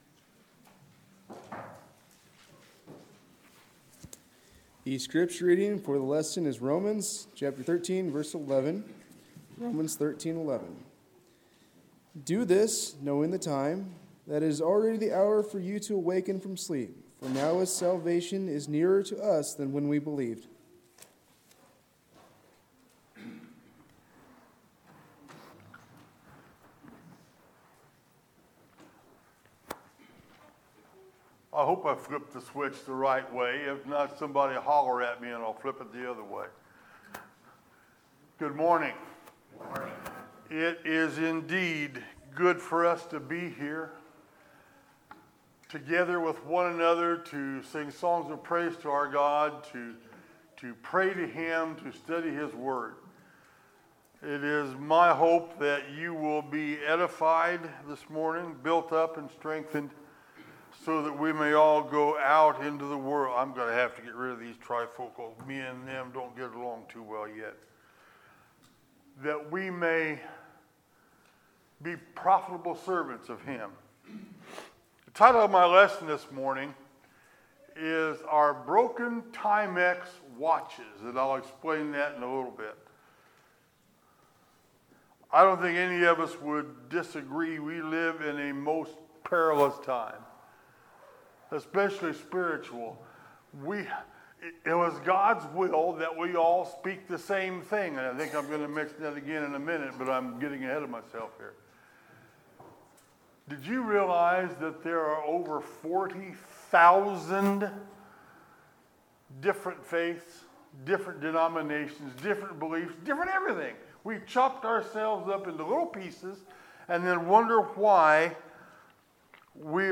Sermons, October 13, 2019